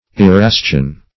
Erastian \E*ras"tian\ (?; 106), n. (Eccl. Hist.)